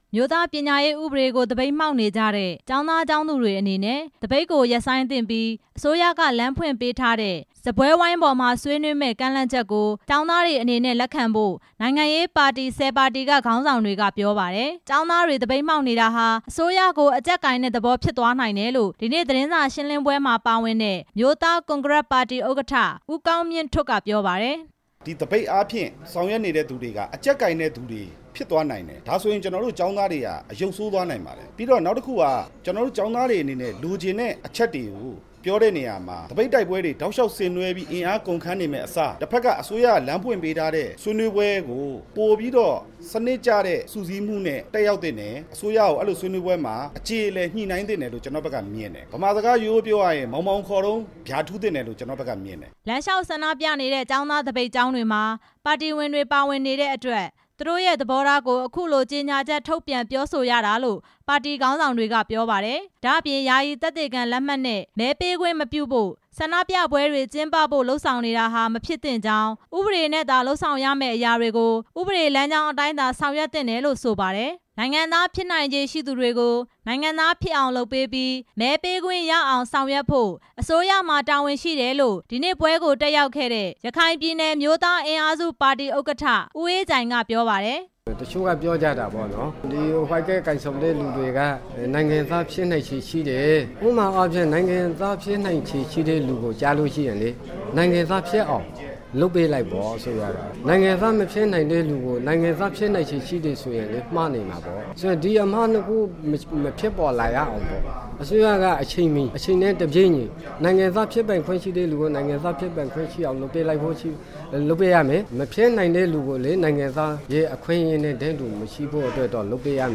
ရန်ကုန်မြို့ City Star Hotel မှာ ဒီနေ့ ကျင်းပတဲ့ သတင်းစာရှင်းပွဲမှာ ပါတီခေါင်းဆောင်တွေက ပြောကြားခဲ့တာပါ။